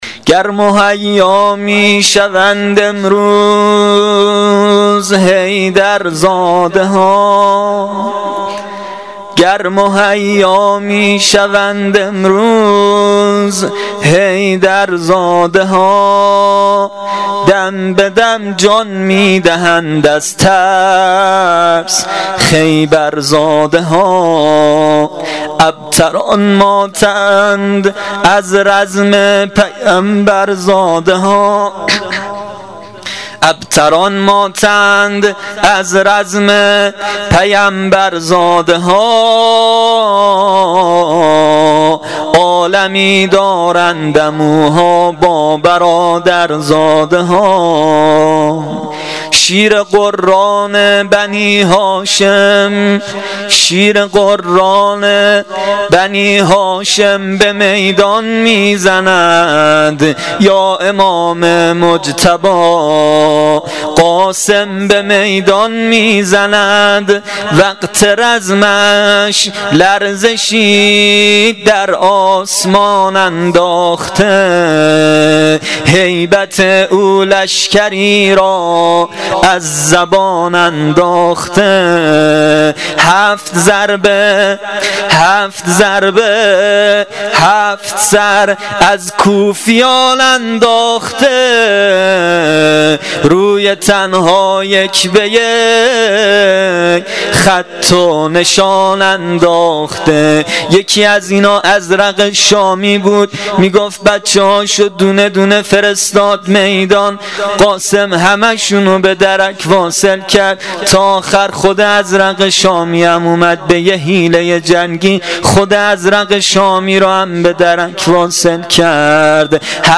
مدح حضرت قاسم شب ششم محرم الحرام 1396